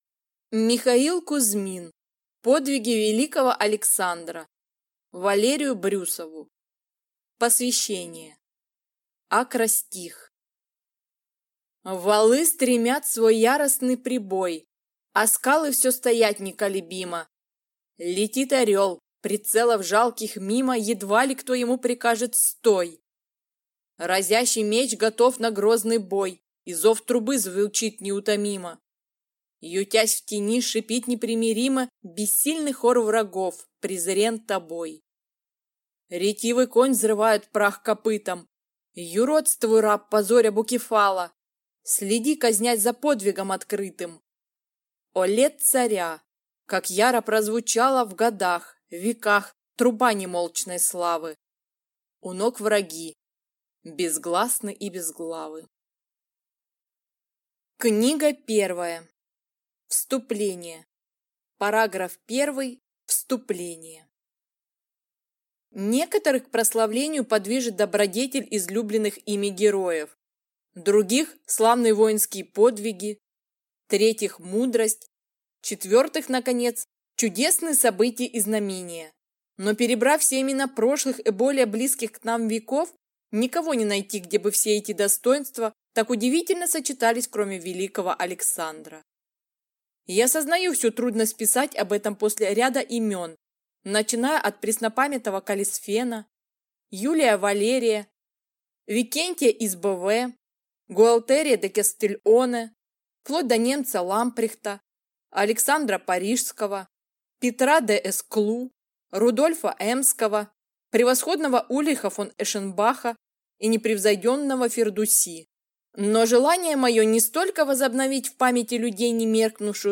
Аудиокнига Подвиги Великого Александра | Библиотека аудиокниг